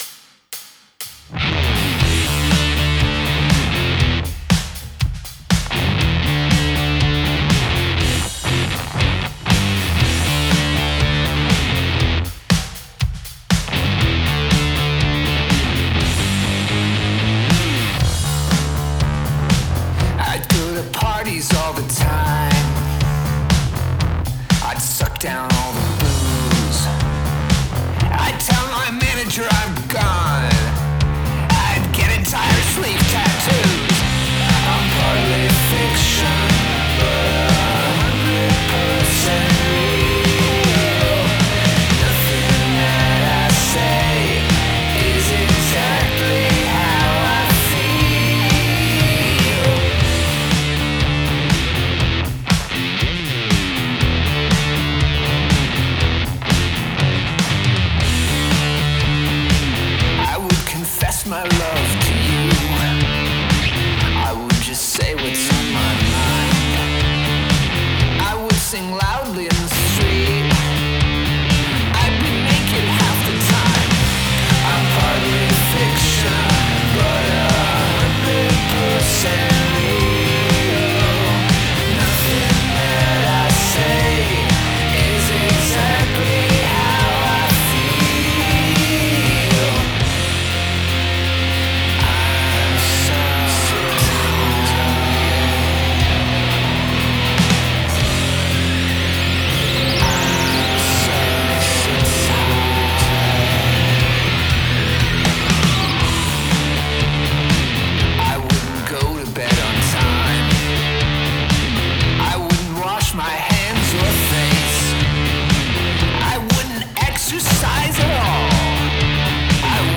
Bad Ass riffs!